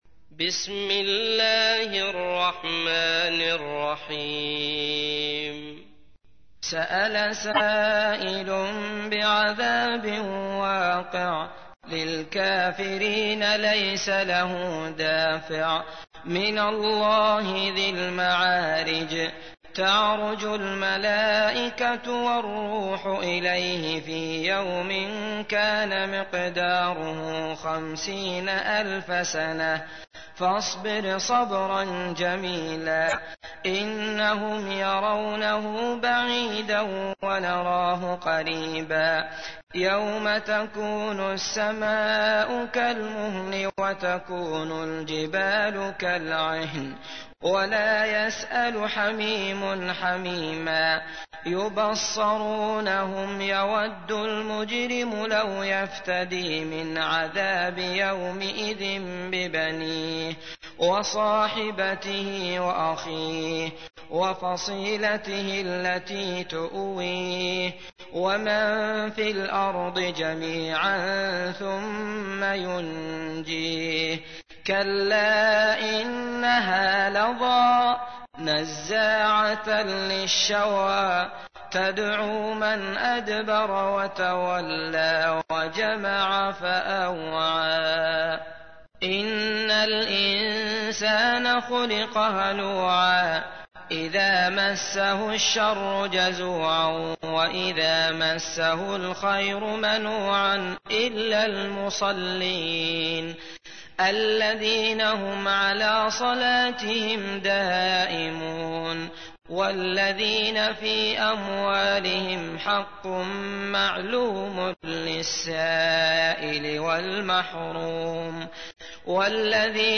تحميل : 70. سورة المعارج / القارئ عبد الله المطرود / القرآن الكريم / موقع يا حسين